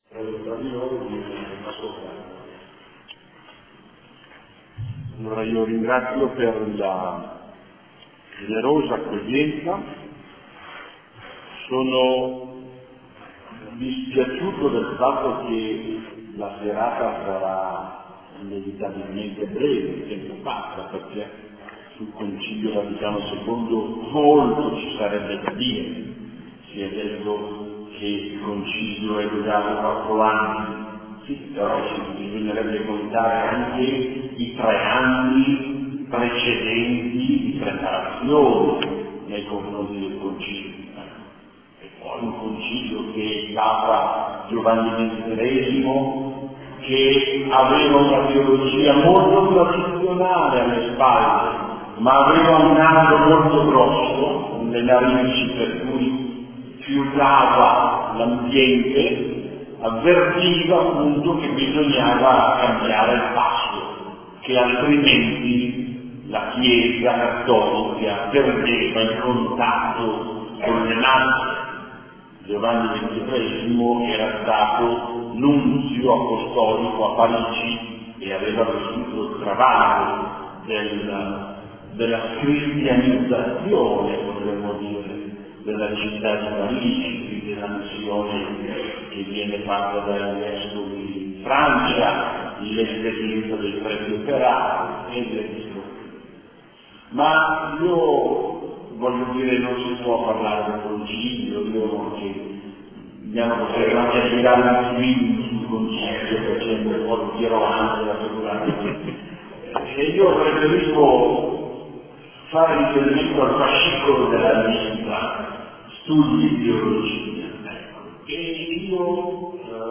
Tavola rotonda: Il Vaticano II visto da fuori: interpretazioni evangeliche « Chiesa Evangelica Riformata Battista, Balsamoxlacittà